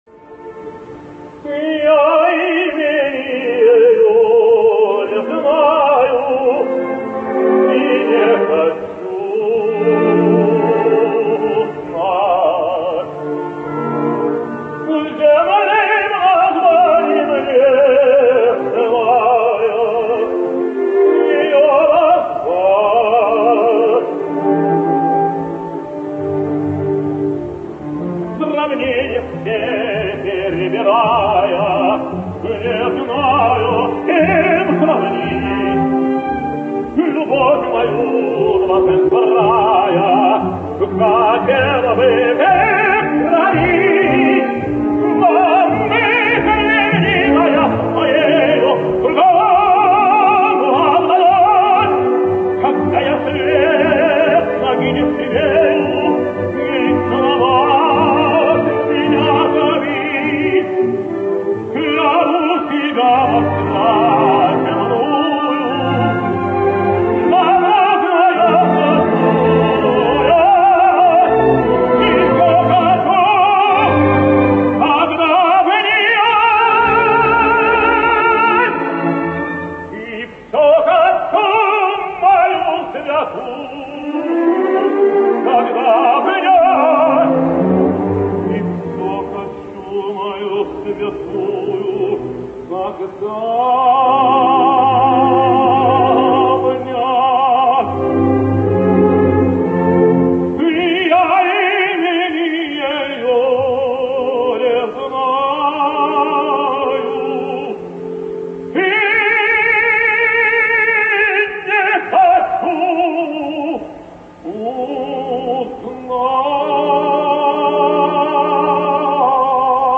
Vladimir Atlantov sings Pique Dame: